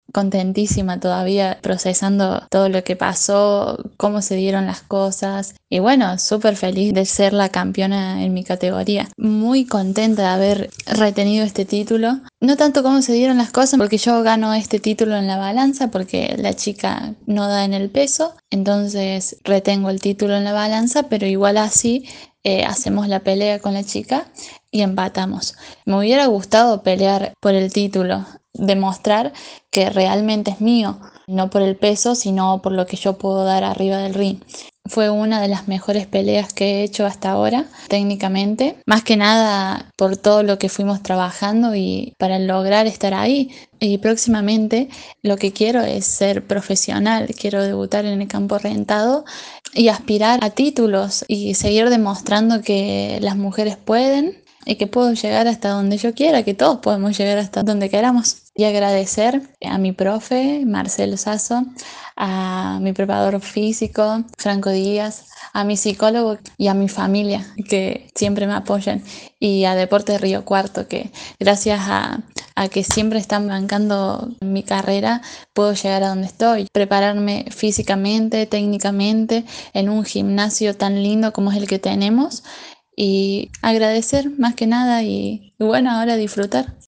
Declaraciones de la campeona